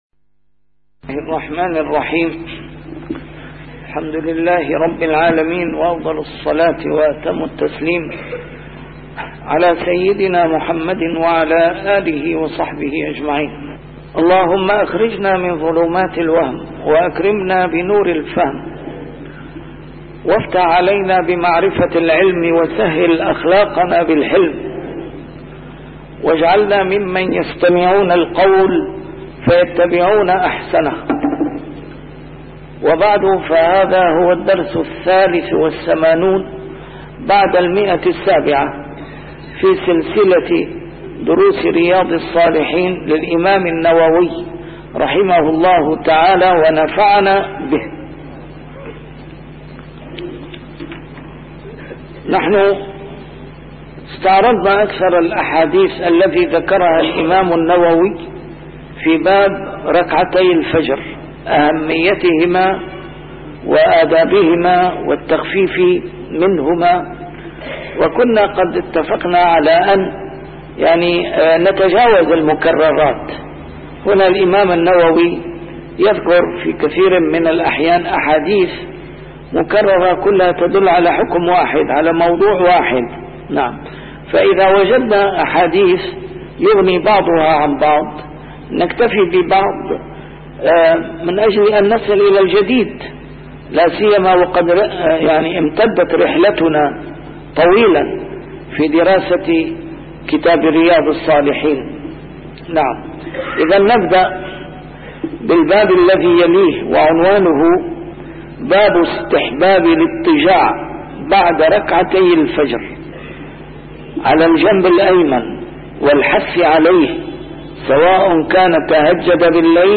شرح كتاب رياض الصالحين - A MARTYR SCHOLAR: IMAM MUHAMMAD SAEED RAMADAN AL-BOUTI - الدروس العلمية - علوم الحديث الشريف - 783- شرح ياض الصالحين: الاضطجاع بعد ركعتي الفجر